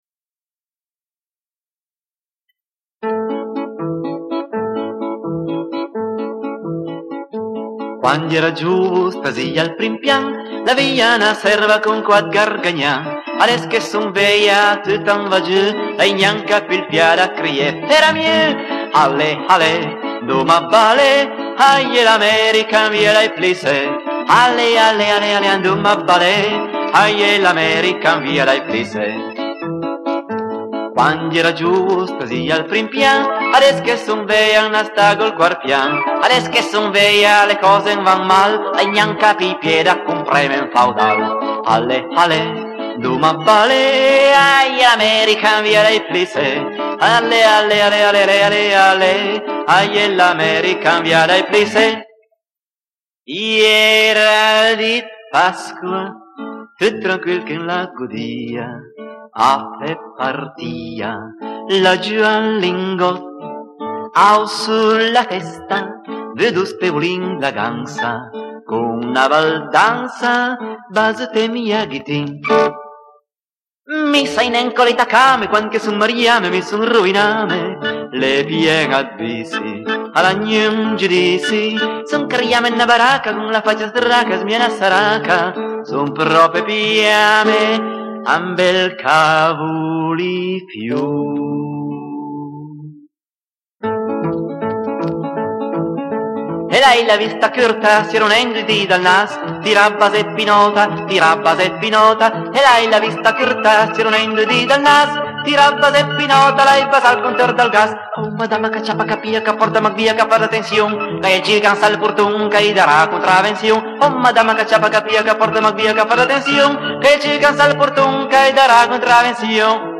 Potpourri